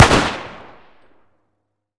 Index of /server/sound/weapons/cw_ar15
fire.wav